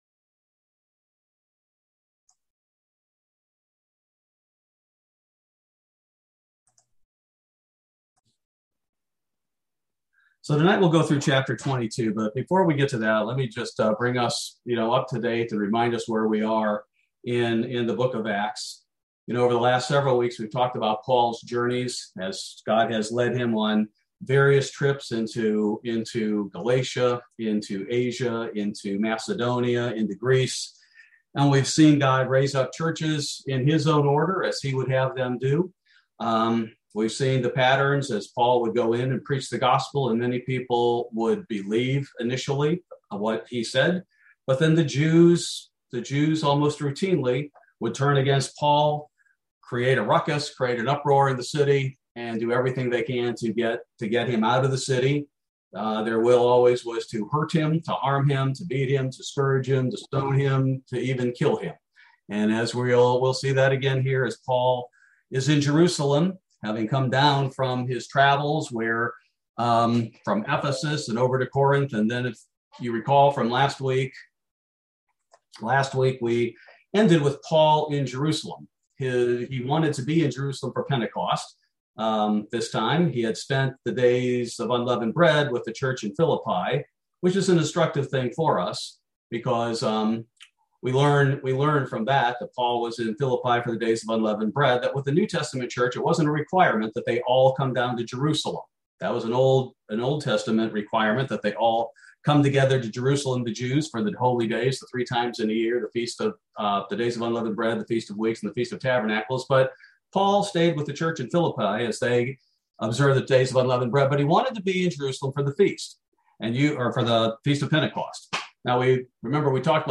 Bible Study: December 29, 2021